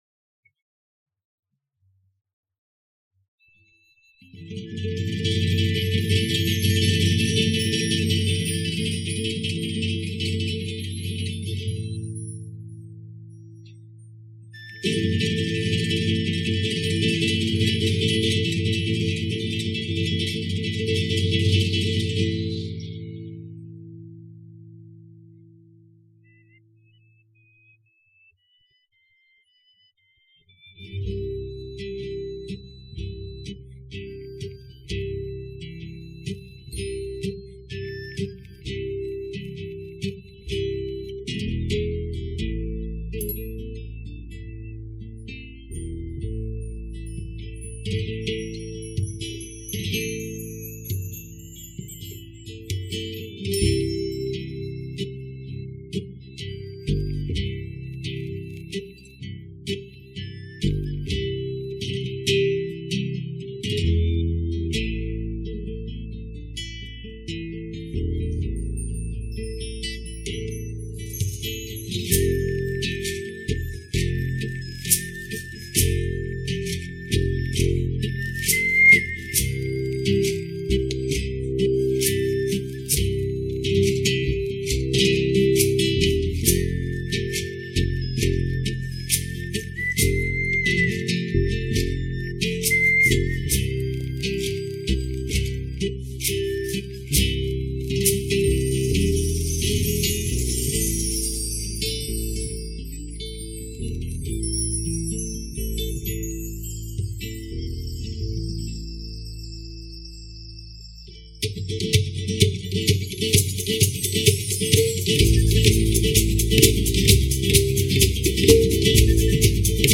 老鹰之歌 12孔G调 -下载地址列表-乐器学习网